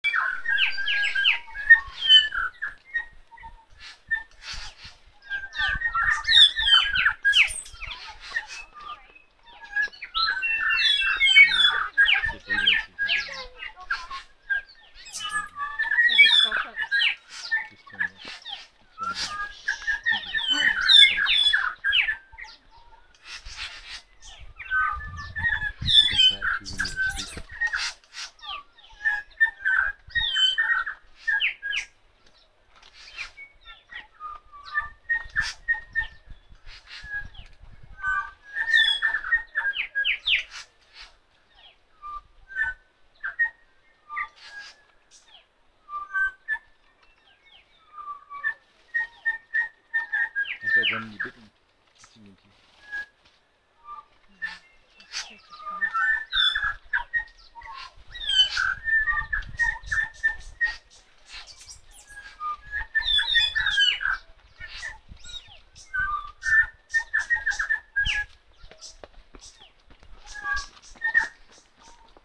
We went to Tiritiri Matangi – a bird sanctuary on an island just off the Whangaparoa Peninsula.
There were at least 20 of them gathered around a feeding station, drunk on nectar and singing their little hearts out [click on the sound bar below the image to listen].
Click below to hear the Bellbirds singing.
bellbird.wav